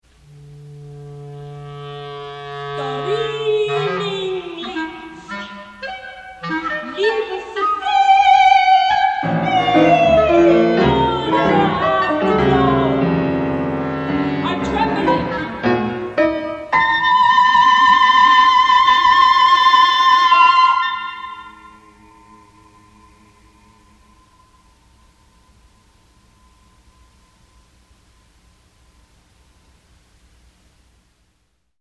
they progress in a fast-slow-fast arrangement